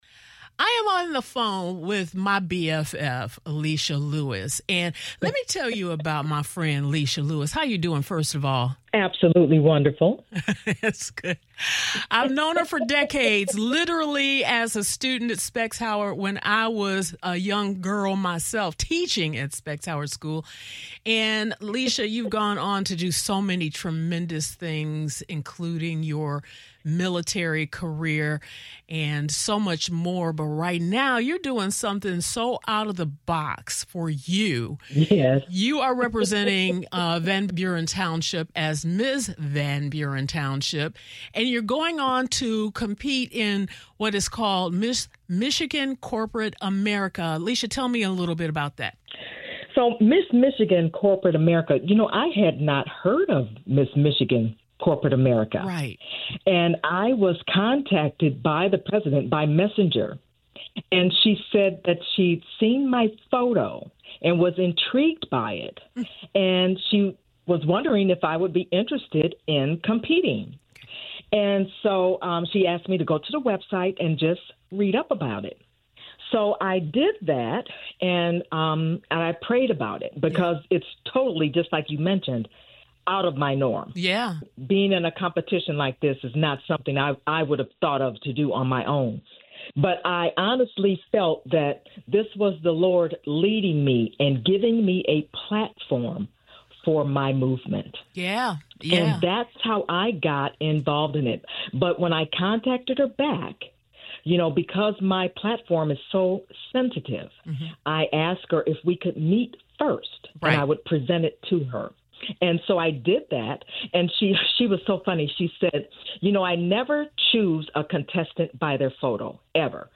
Now let me be clear—this is more than an interview.